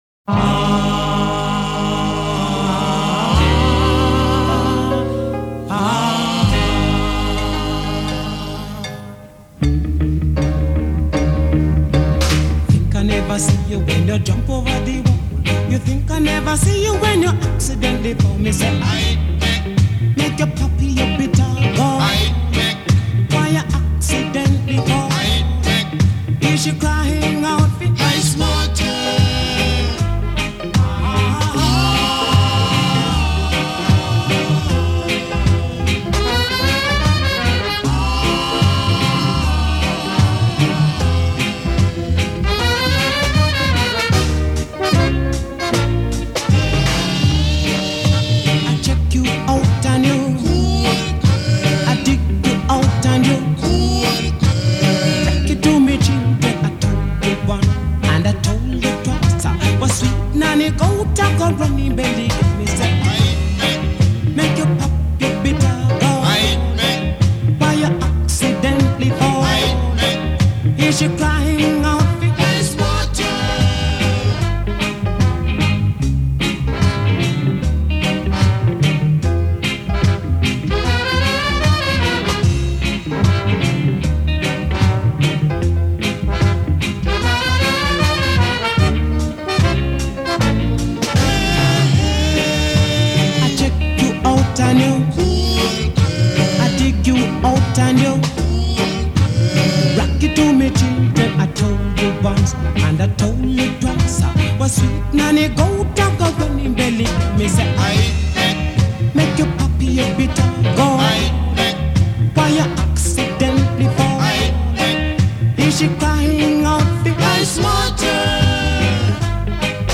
A proper slice of lilting rudeboy reggae